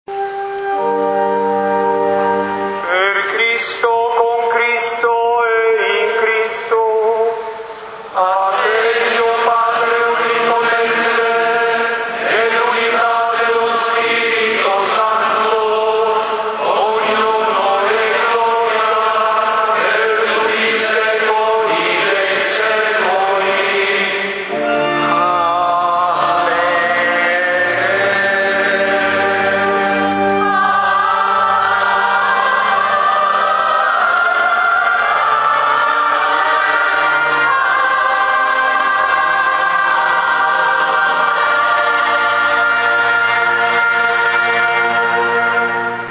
Gallery >> Audio >> Audio2013 >> Ingresso Arcivescovo Pennisi >> mp3-Dossologia
mp3-Dossologia